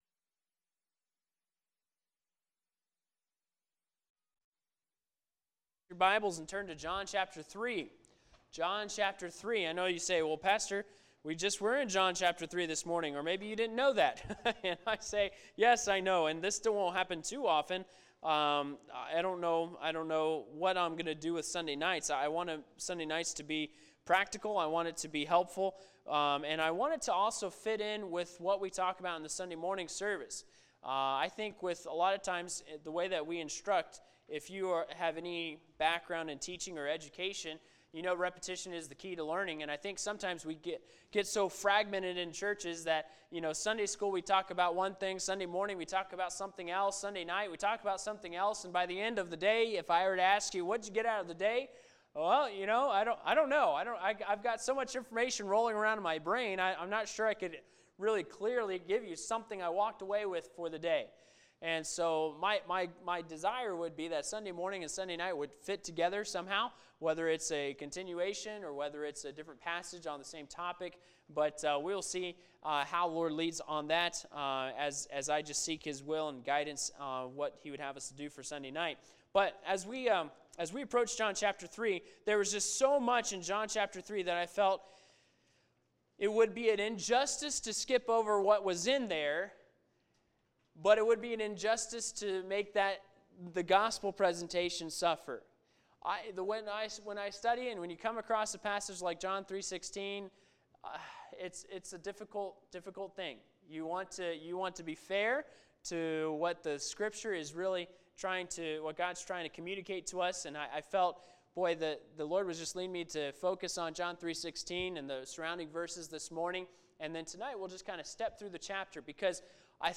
Sermons | Anthony Baptist Church